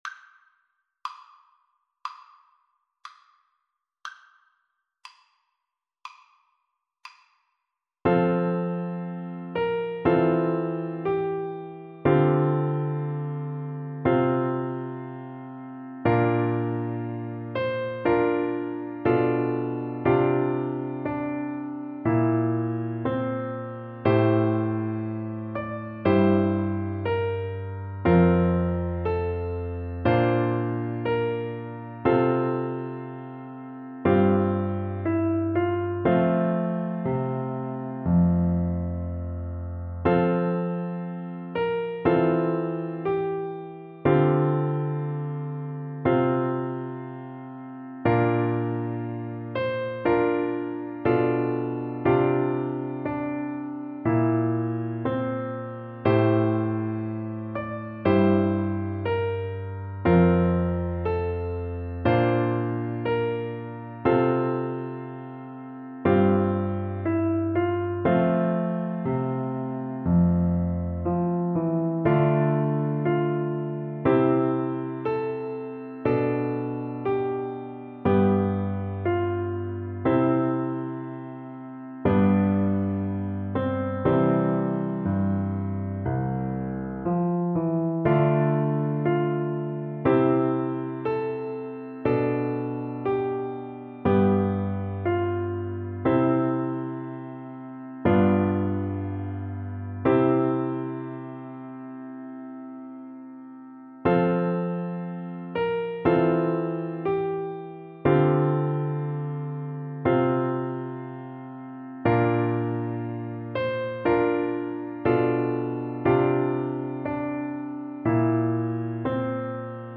4/4 (View more 4/4 Music)
Andante
C5-D6